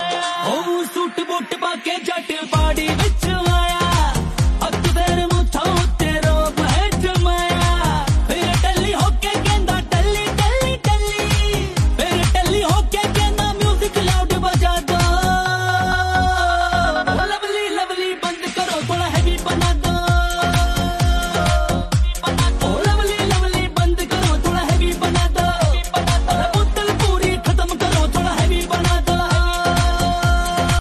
Punjabi Songs
• Simple and Lofi sound
• Crisp and clear sound